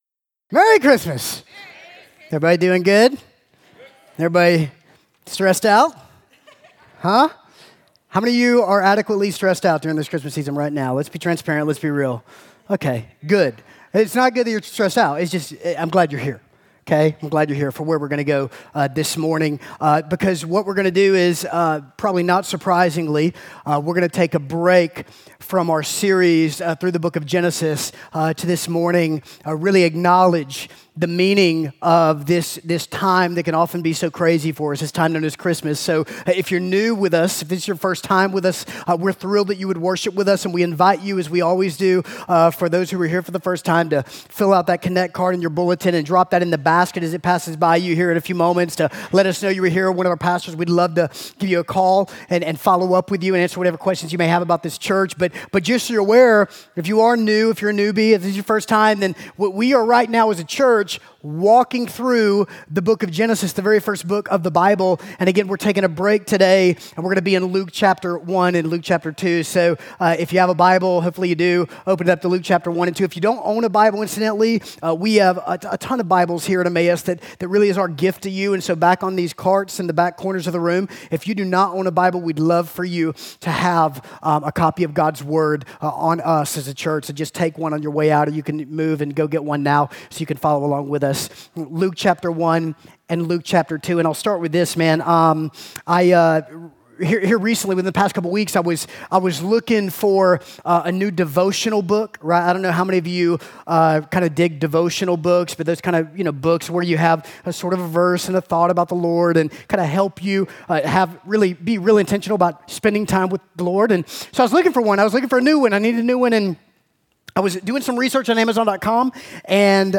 A Christmas Sermon (Luke 1:26-28, 46-55; 2:8-14, 21-38)